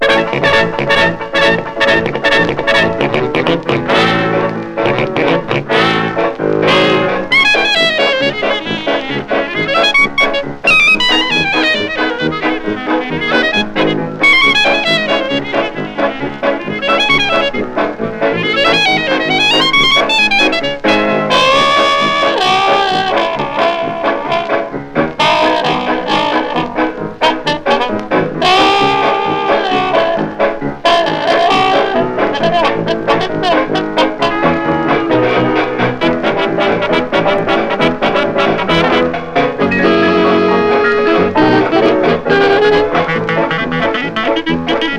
音の抜き差し、機知に富んだアレンジが素晴らしい。テンション高め、興奮必至の演奏は圧巻です。
Jazz　Canada　12inchレコード　33rpm　Mono